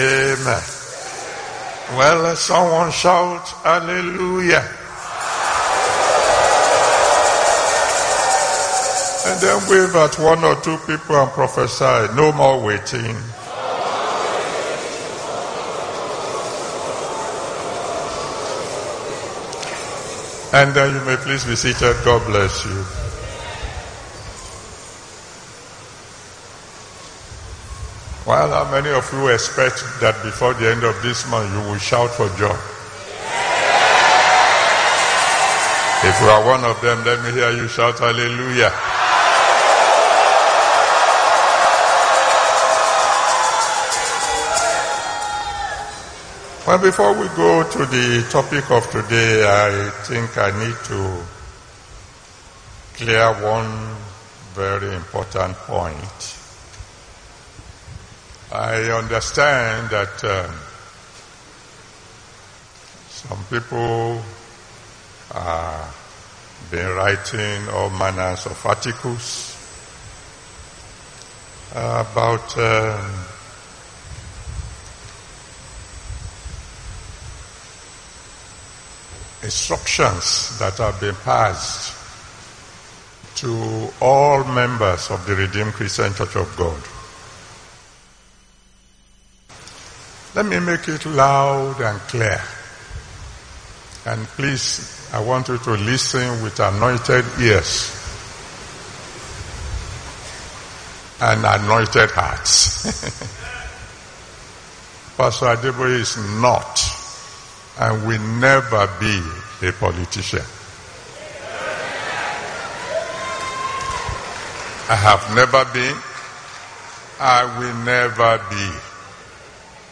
RCCG APRIL 2022 THANKSGIVING SERVICE – PASTOR E.A ADEBOYE